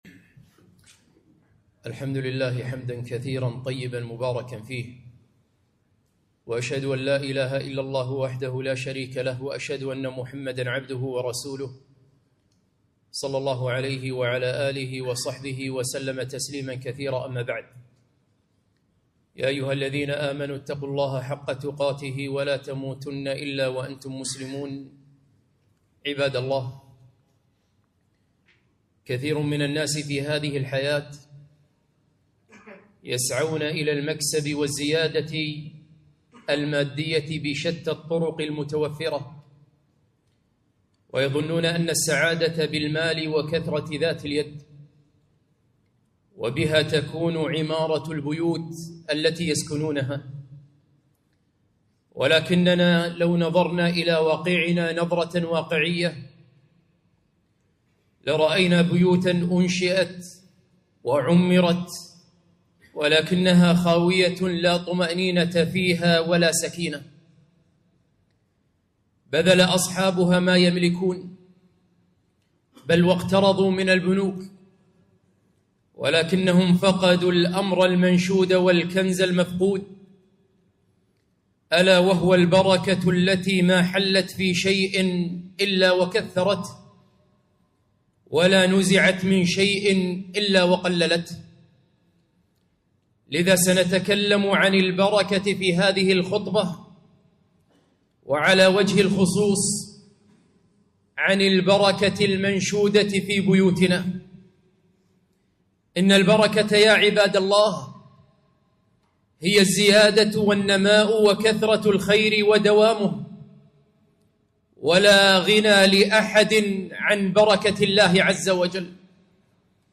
خطبة - كيف تحقق البركة في بيوتنا؟